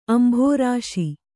♪ ambhōrāśi